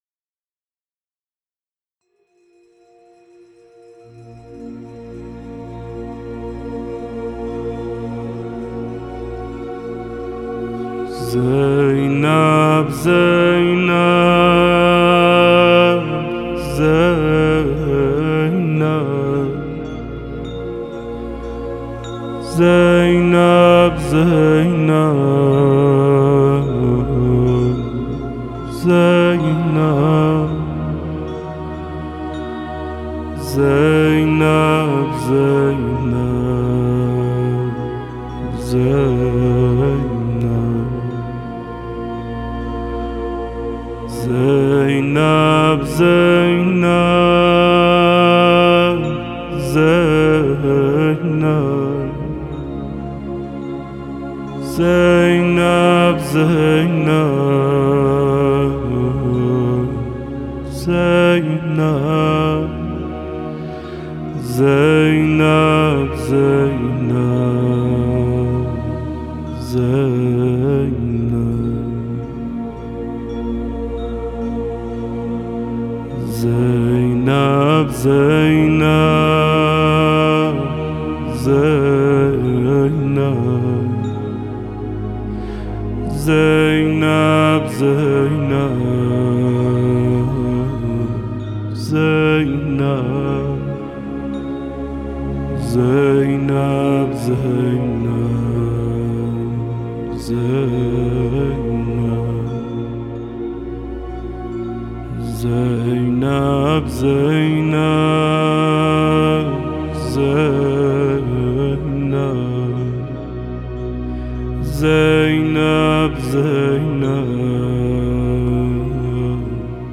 خواننده نواهای مذهبی